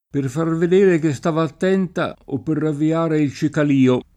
cicalio [ © ikal & o ] s. m.